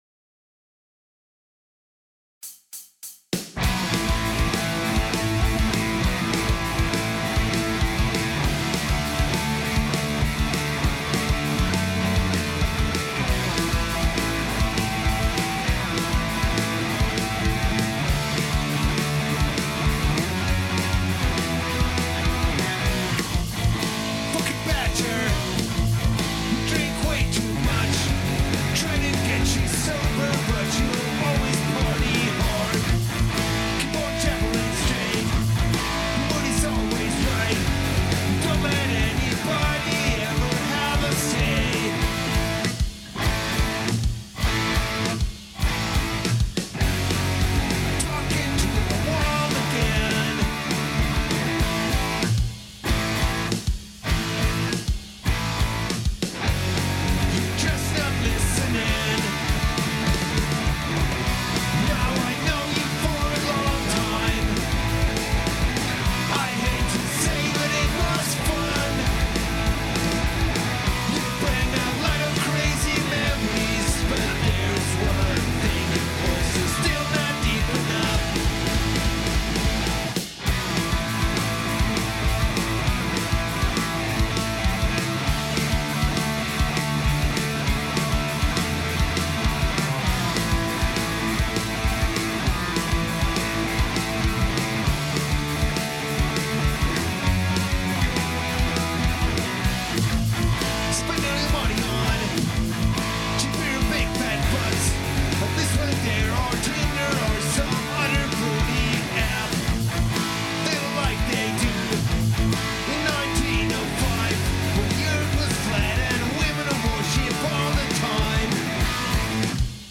Sind eine Punk-Rock / Fun Punk Band aus dem Berner Oberland.